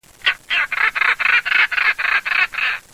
Kraska - Coracias garrulus
głosy
kraska.mp3